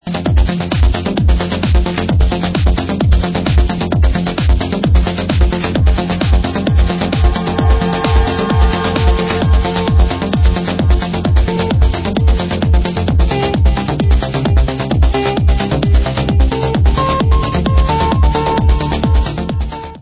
sledovat novinky v oddělení Dance/Trance